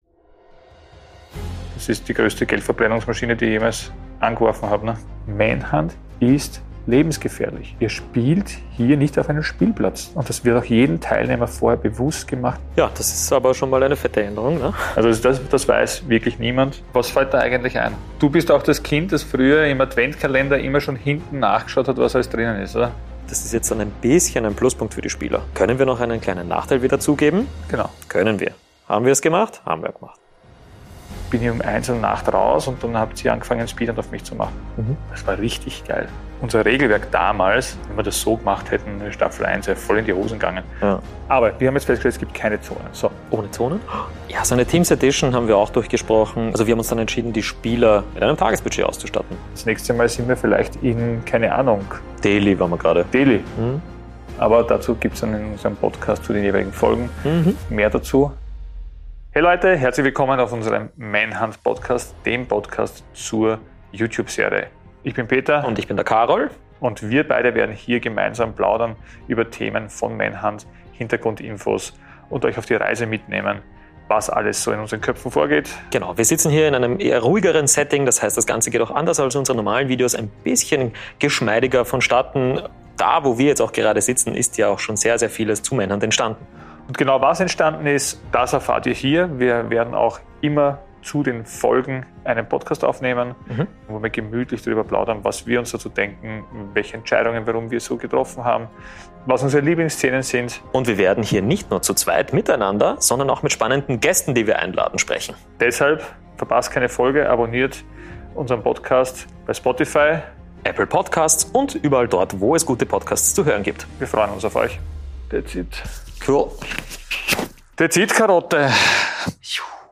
Trailer: Das erwartet euch im Manhunt Podcast
Und hört den beiden gewohnt authentisch beim Plaudern zu.